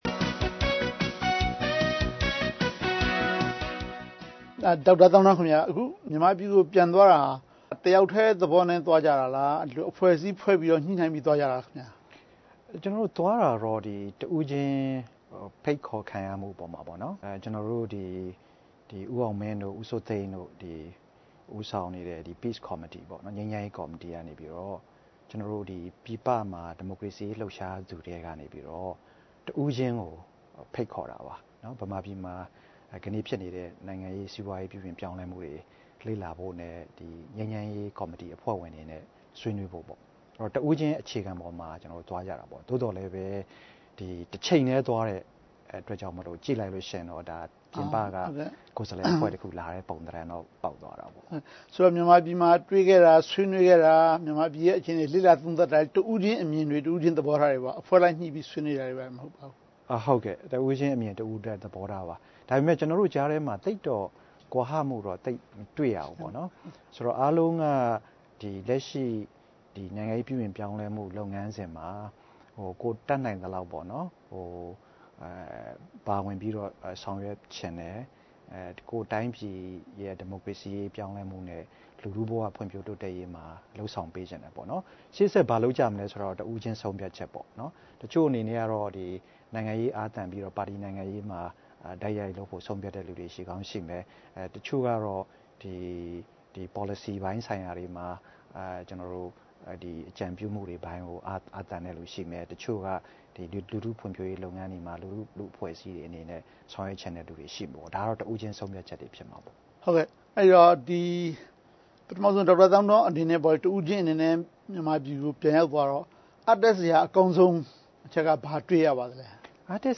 News Analysis - Interview